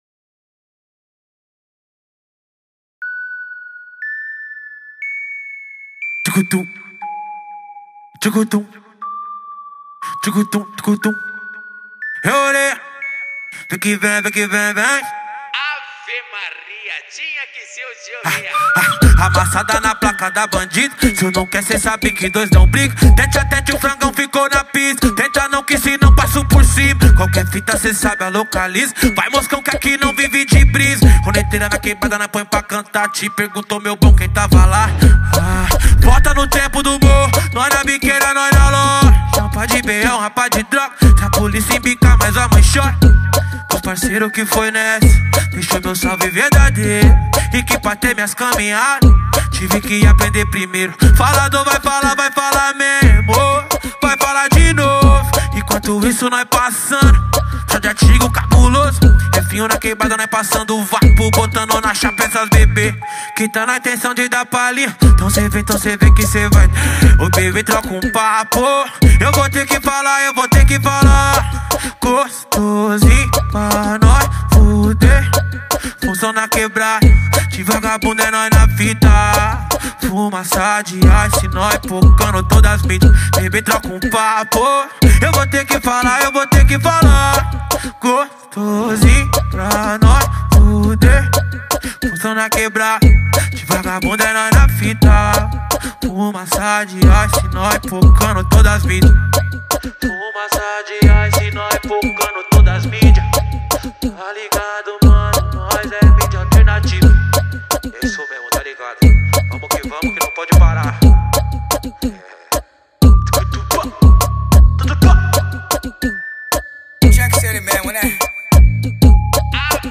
2024-12-10 18:11:29 Gênero: Funk Views